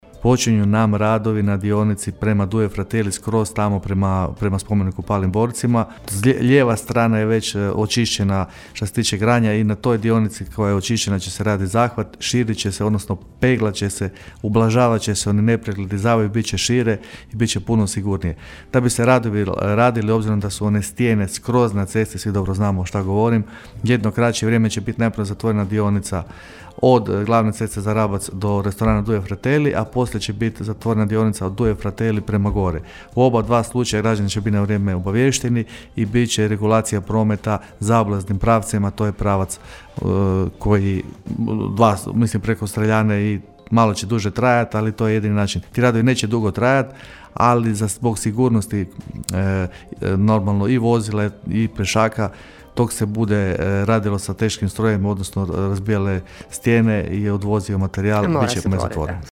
Najavio je Glavičić kako će odmah nakon Uskrsa početi radovi na cesti za Ripendu: (